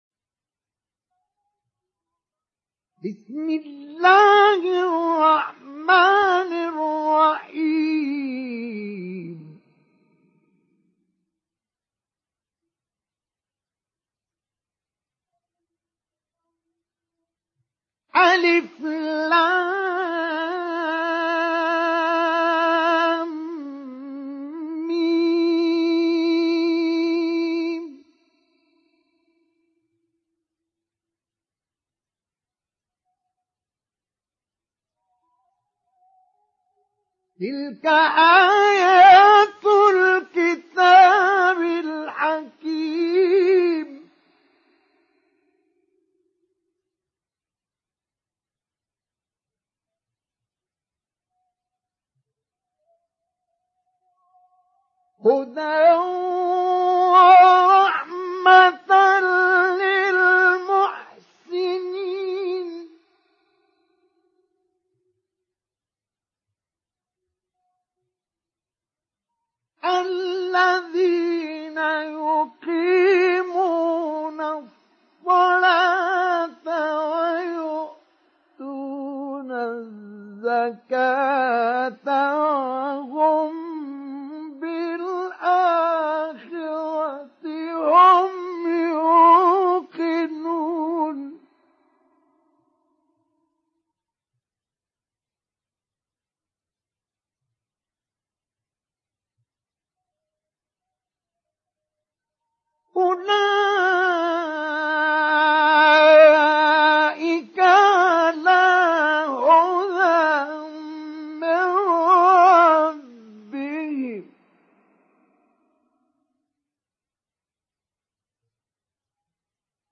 Surat Luqman Download mp3 Mustafa Ismail Mujawwad Riwayat Hafs dari Asim, Download Quran dan mendengarkan mp3 tautan langsung penuh
Download Surat Luqman Mustafa Ismail Mujawwad